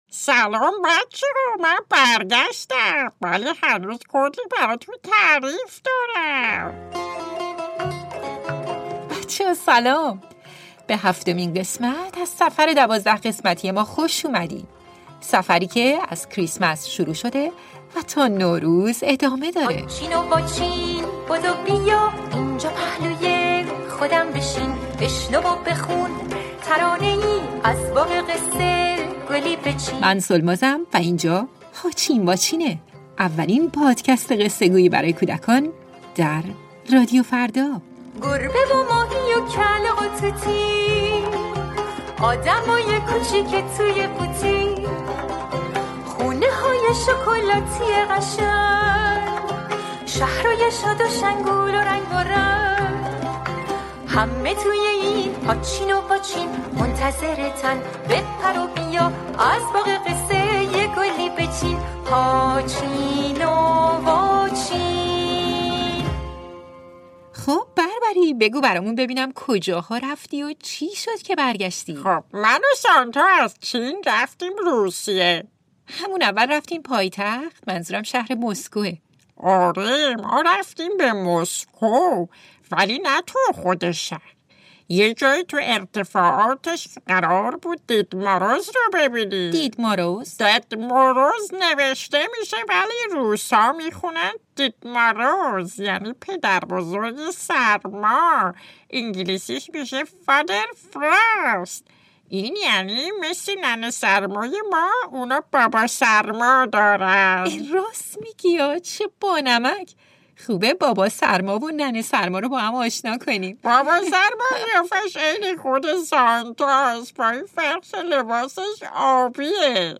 توی این قسمت با اسنگروچکا هم آشنا می‌شیم که نوه و دستیار بابا برفی یا همون سانتای آبیه. افسانه اسنگروچکا یا دختر برفی رو همراه من و بربری بشنوید. در ضمن تمام موسیقی‌های این قسمت برگرفته از موسیقی‌های محلی روسیه است.